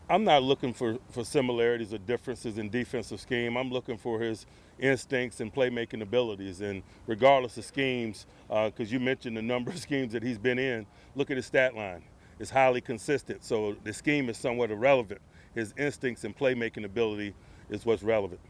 Head Coach Mike Tomlin talked about how his new linebacker will fit into the Steelers’ defensive mold. He said the big thing to look for in Schobert is his consistency.